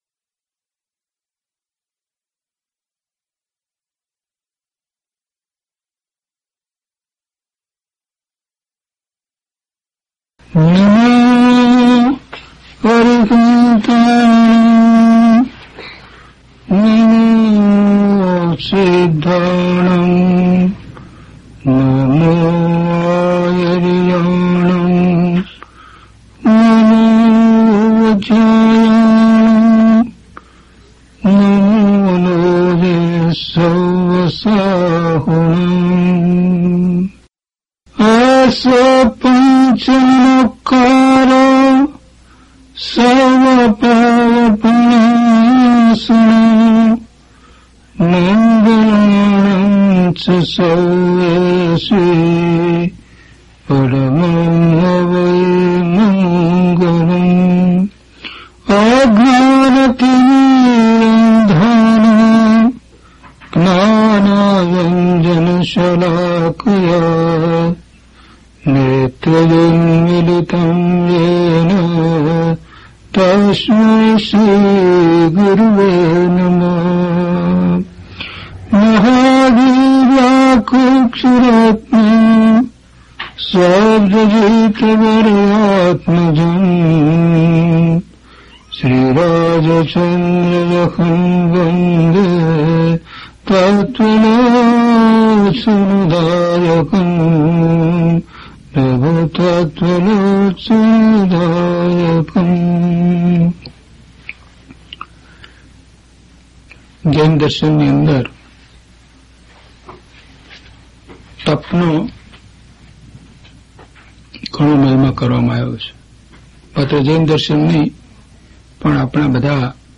DHP041 Tap Ane Pratikraman - Pravachan.mp3